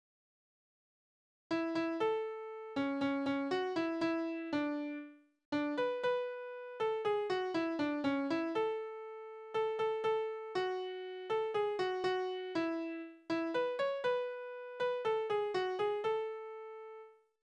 Tonart: A-Dur
Taktart: 4/4
Tonumfang: Oktave
Besetzung: vokal